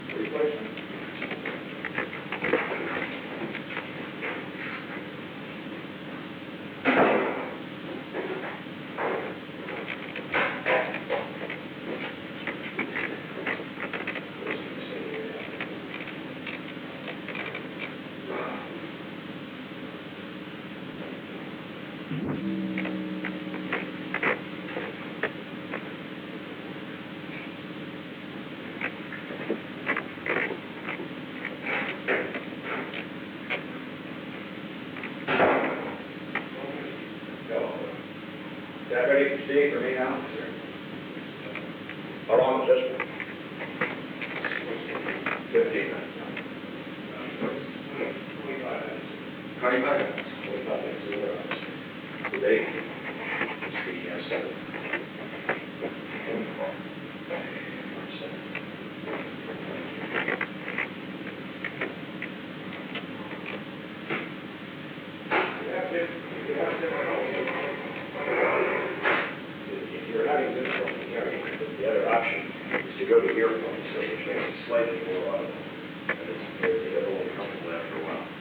Secret White House Tapes
Conversation No. 442-33
Location: Executive Office Building